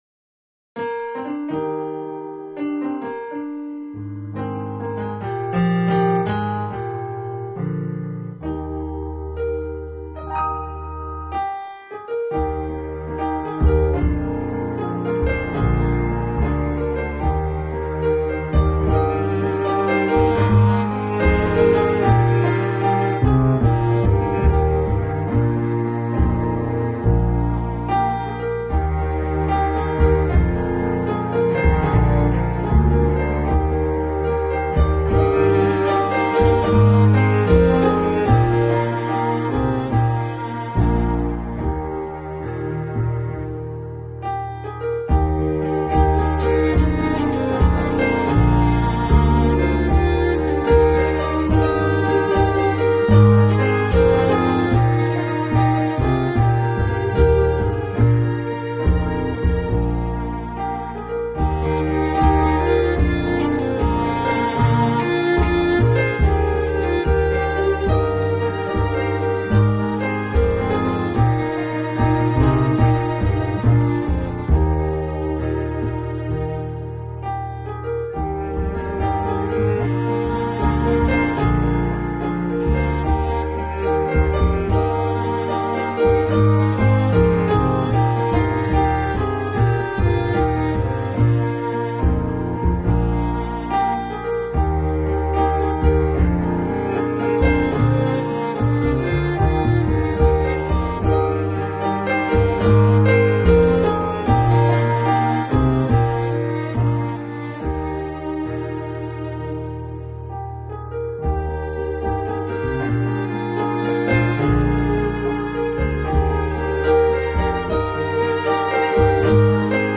Accompagnement de la chanson du sketch
accomp_pour_des_prunes.mp3